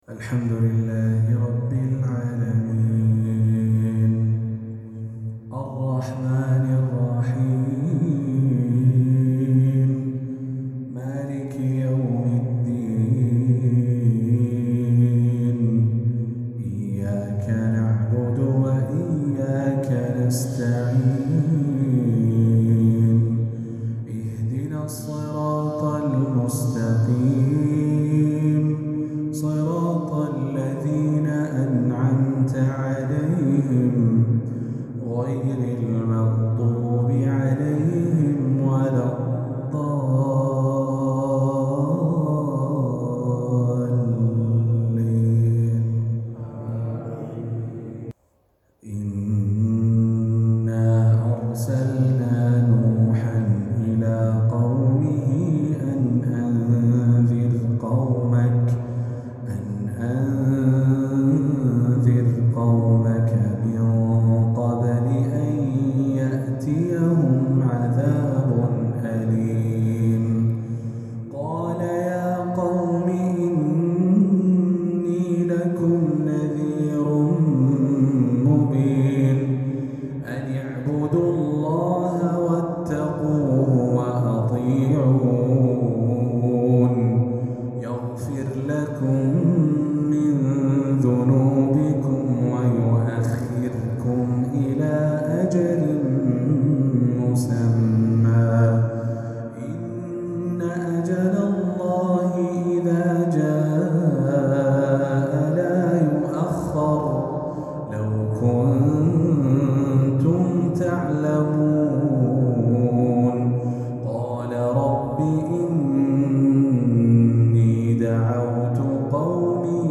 عشائية جميلة